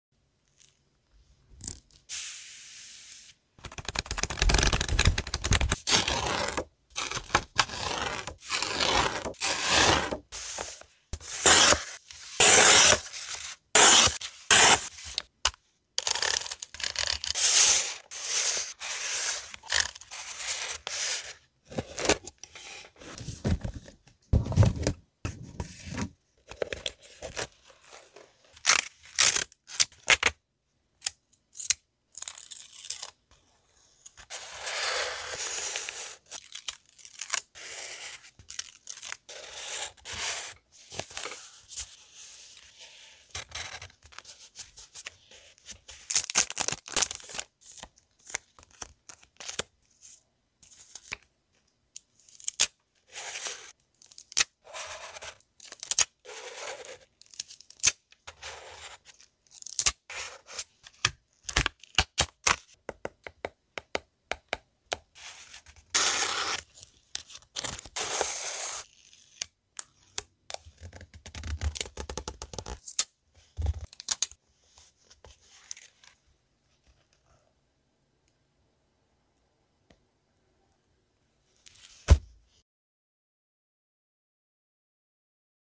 ASMR | Christmas Bakery Goods sound effects free download